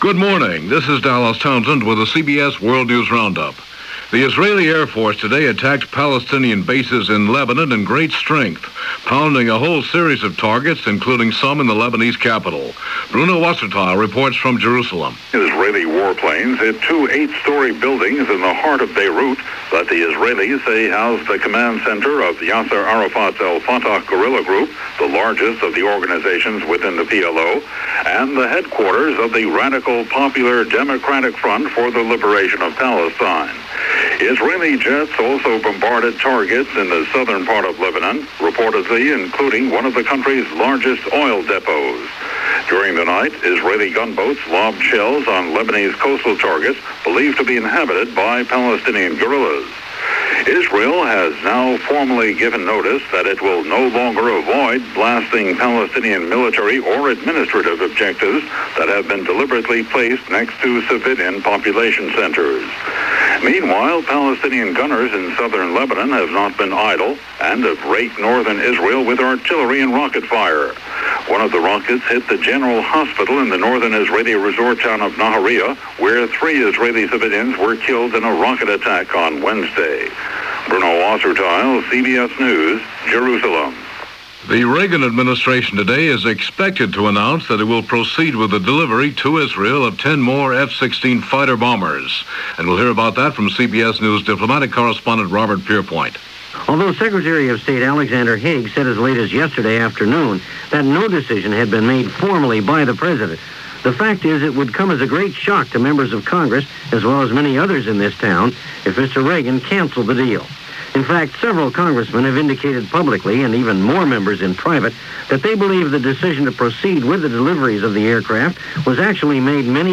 July 17, 1981 - Orange Skies Over Beirut - Taking Sides - Kremlin Anxiety Over Poland - news for this day in 1981 as reported on The CBS World News Roundup.